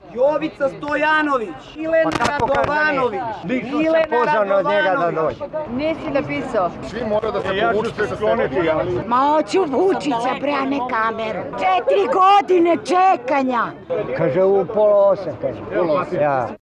Dok ih je obezbeđenje pojedinačno prozivalo, iz mase se povremeno čulo i negodovanje, jer u prvom trenutku neki od prijavljenih koji su prethodno pozvani telefonom ili e-mailom, nisu bili na spisku.
Atmosfera kod Vučića